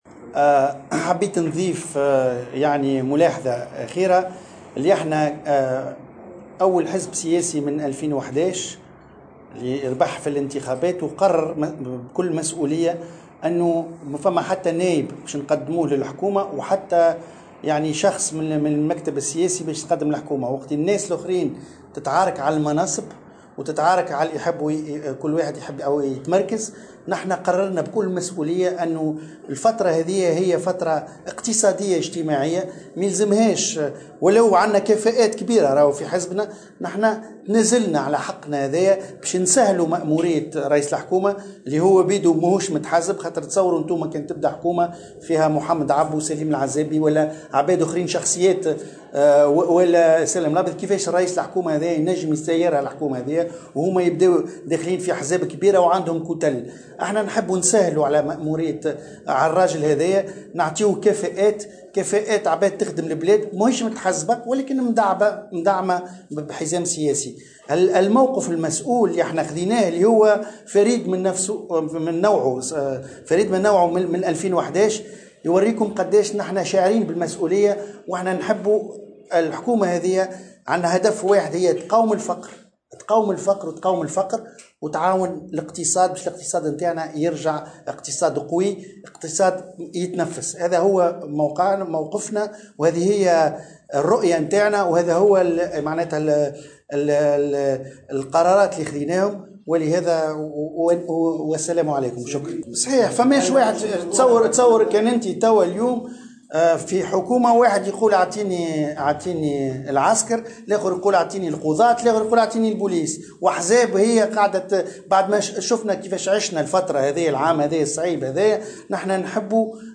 وأعلن القروي في تصريح لمراسلة "الجوهرة اف ام" استعداده لتوفير كفاءات لهذه الحكومة، وذلك على اثر اجتماع المكتب السياسي لحزب قلب تونس اليوم الأحد وتابع برنامج حزبه وأهدافه هو مقاومة الفقر، مشدّدا على أهمية حيادية وزارات السّيادة وعلى رأسها الدفاع والداخليّة.